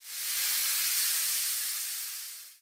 WheelInflate.wav